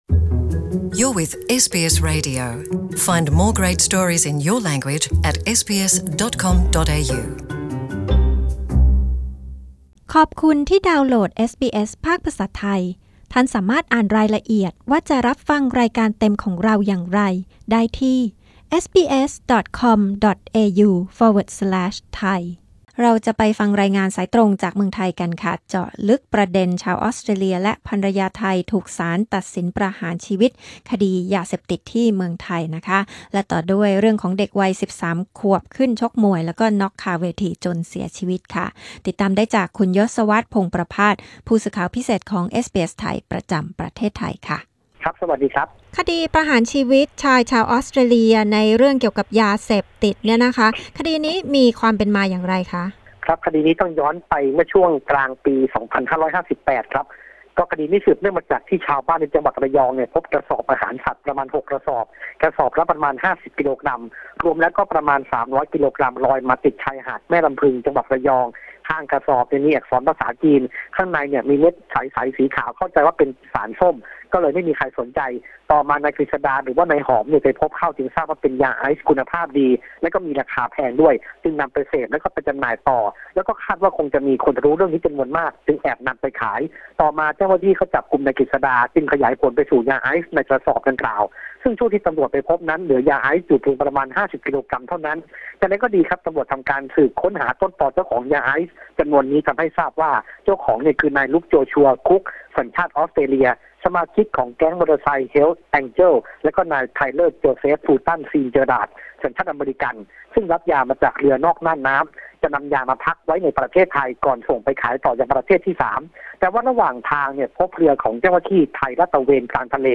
Thai news report Nov 15